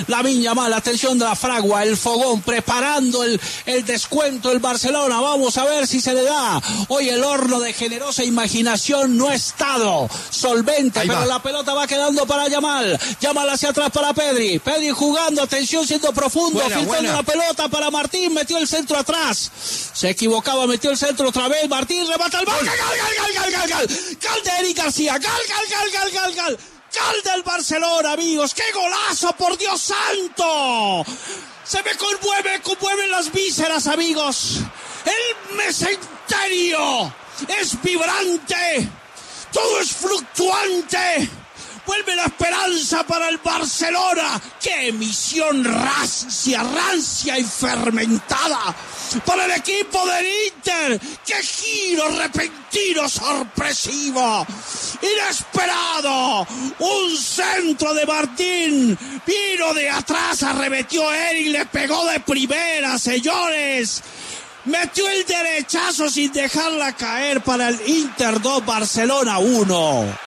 Así fue la narración de Martín de Francisco del gol del Barcelona: